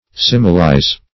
Meaning of similize. similize synonyms, pronunciation, spelling and more from Free Dictionary.
Search Result for " similize" : The Collaborative International Dictionary of English v.0.48: Similize \Sim"i*lize\, v. t. To liken; to compare; as, to similize a person, thing, or act.